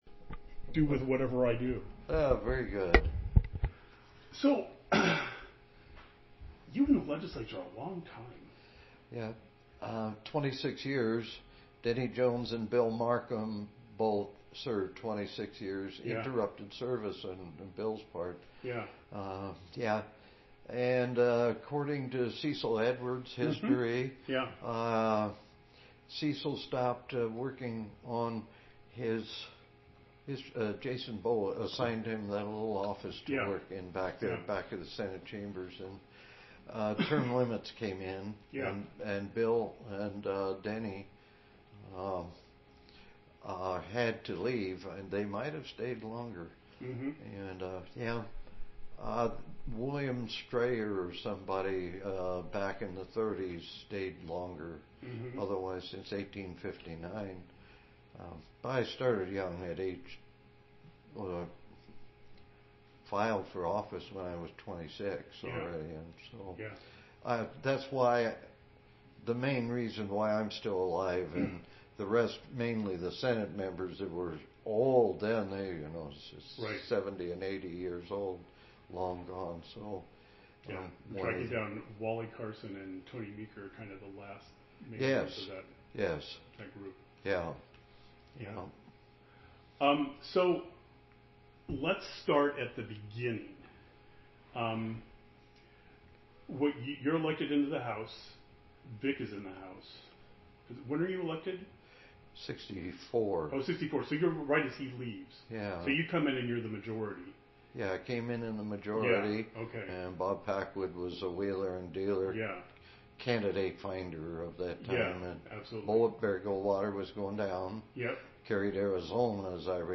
fdd1f4b713279b604faf3fcd564c19063a7492c5.mp3 Title Paul Hanneman interview on Atiyeh Description An interview of Paul Hanneman on the topic of Oregon Governor Vic Atiyeh, recorded on July 22, 2015. Hanneman was a Republican serving in the Oregon House of Representatives from 1965-1990.